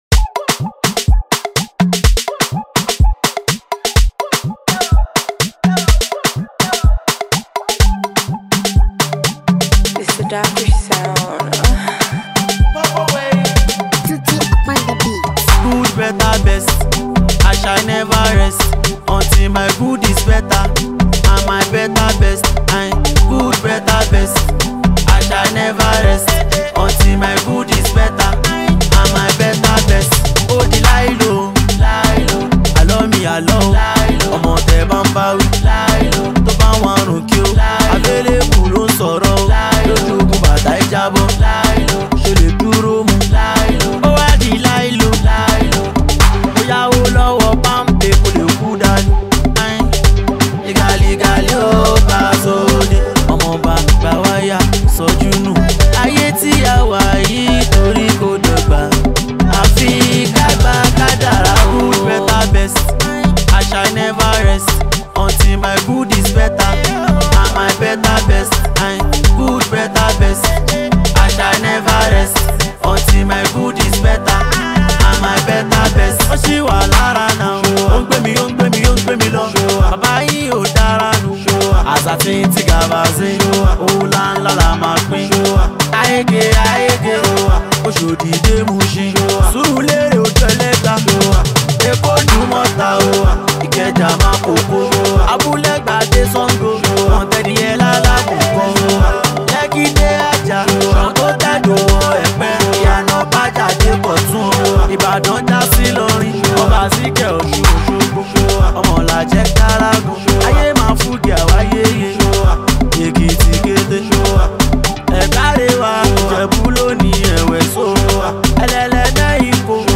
dance track